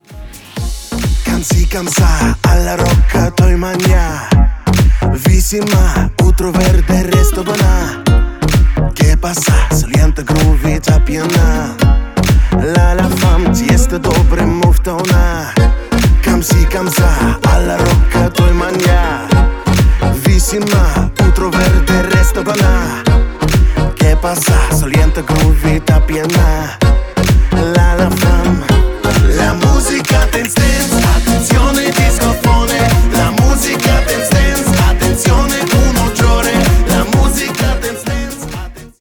• Качество: 320, Stereo
мужской вокал
зажигательные
латиноамериканские
Latin Pop